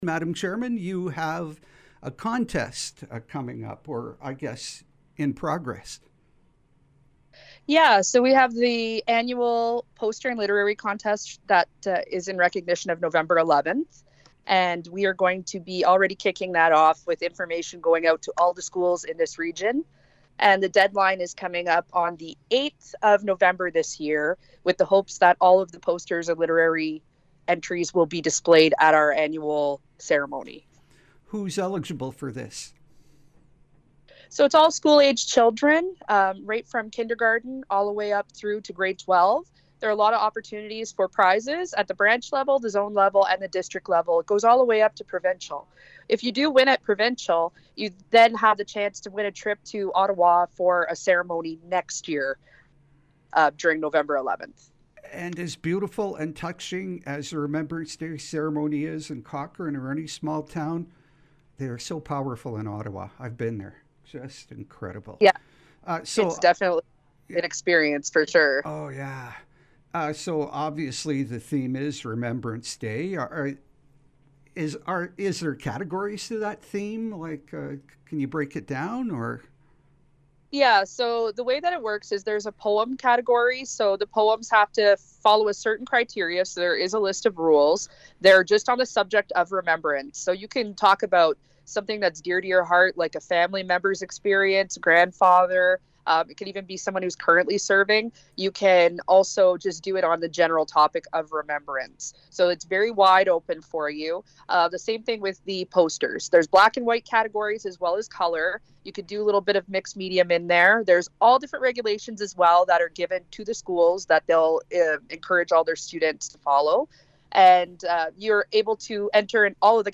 It’s also in the audio of our interview.